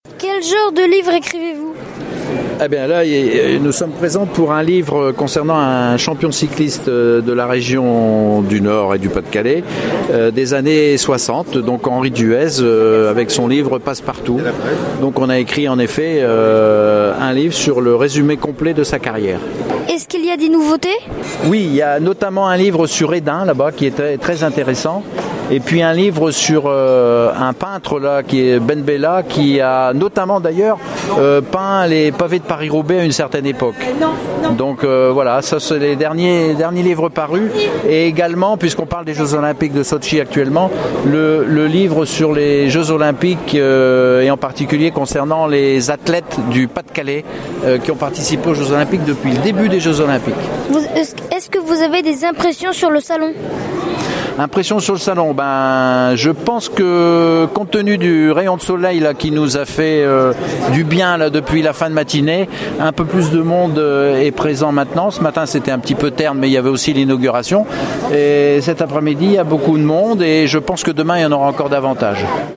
VOICI LES REPORTAGES